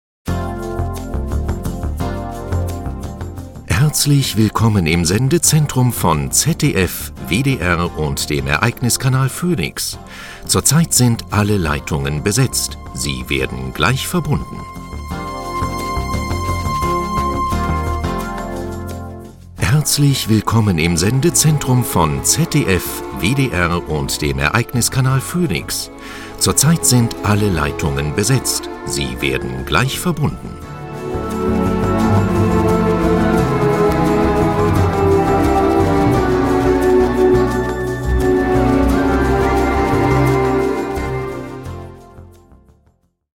Sprecher deutsch.
Sprechprobe: Sonstiges (Muttersprache):
german voice over artist